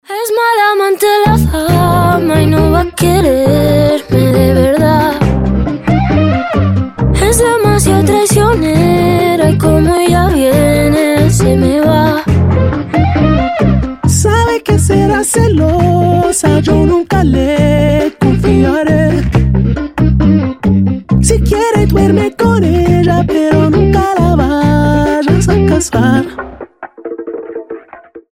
• Качество: 320, Stereo
заводные
дуэт
латина